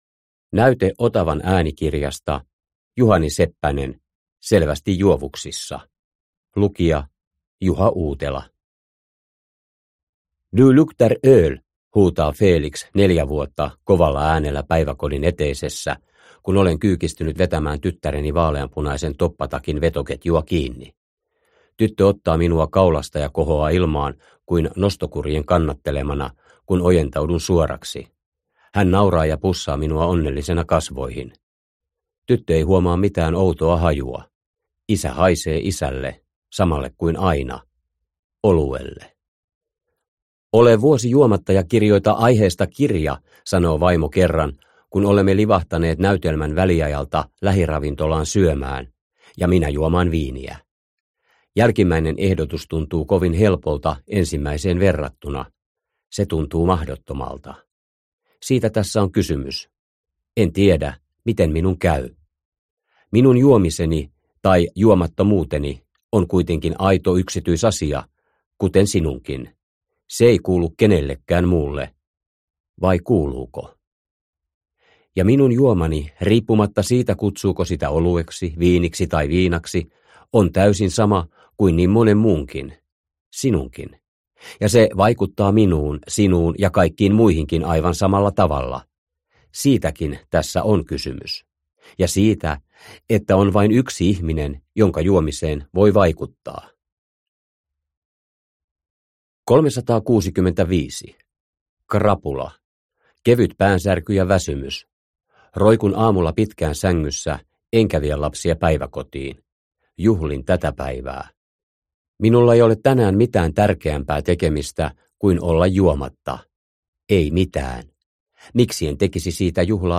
Selvästi juovuksissa – Ljudbok – Laddas ner